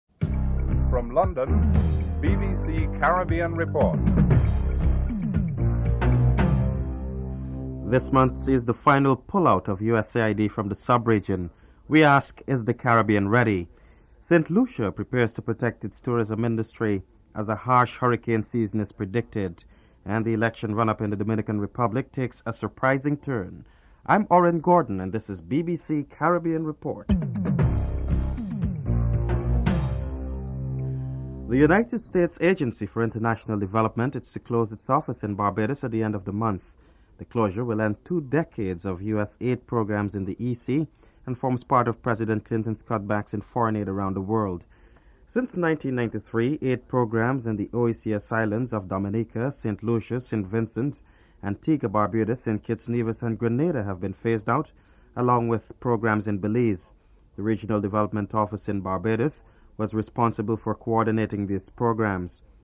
1. Headlines (00:00-00:30)
Labour Member of Parliament Bernie Grant is interviewed (04:54-07:30)
PNC leader Desmond Hoyte is interviewed (13:37-15:16)